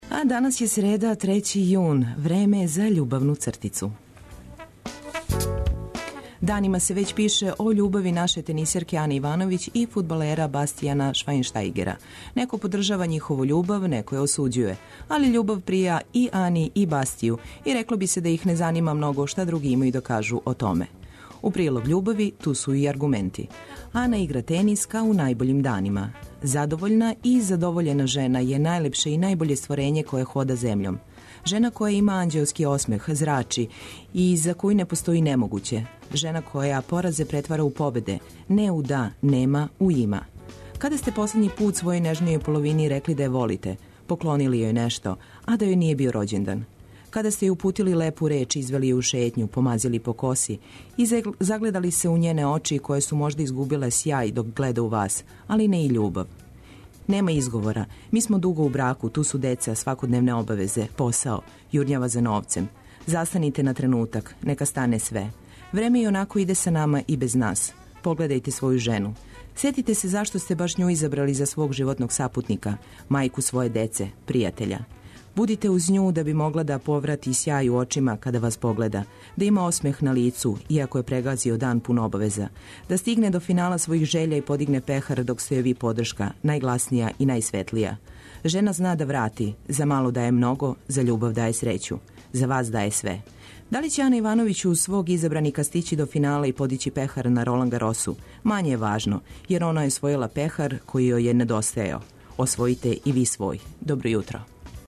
Добра музика, сервисне, културне и спортске информације, прошараће још један Устанак од 6 до 9. Цртица ће Вам пожелети добро јутро, Графити измамити осмех, Квака дати своје виђење стварности, Хиљаду и један траг негује нашу традицију.